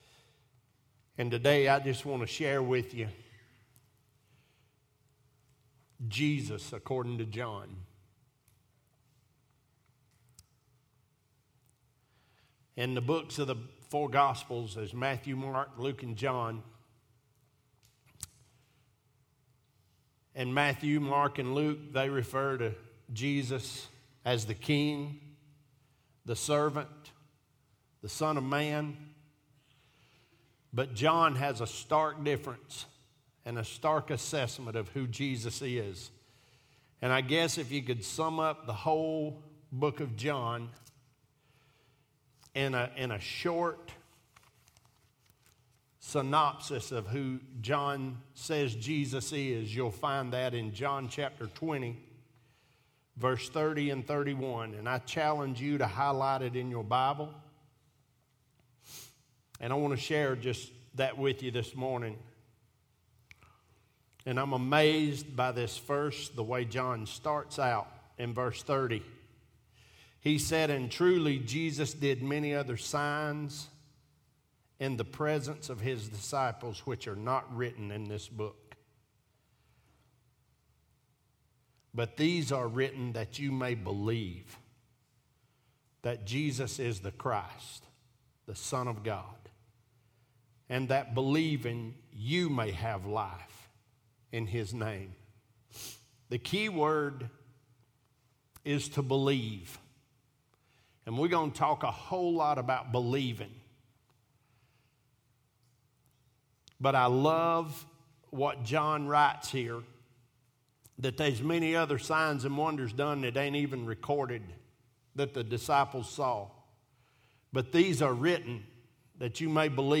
Sermons | Living for the Brand Cowboy Church of Athens